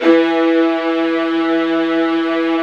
55u-va02-E2.aif